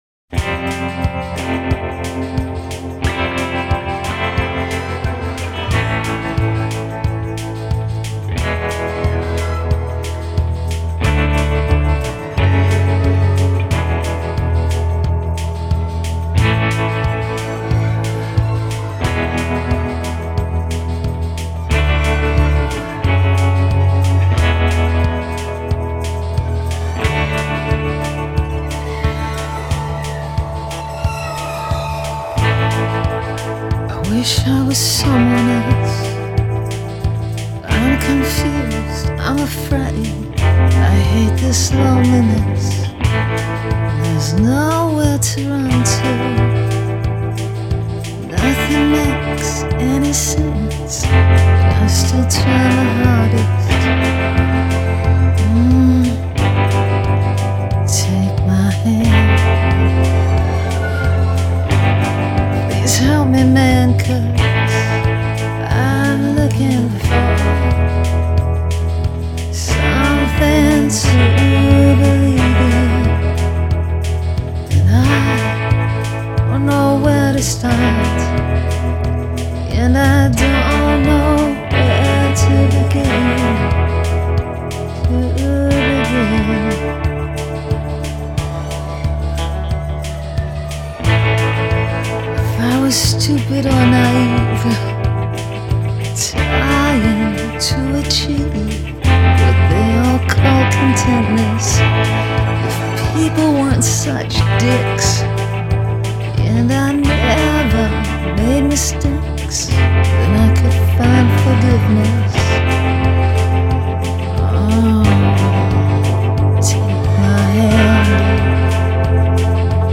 Total change in mood and makes the song all the better. http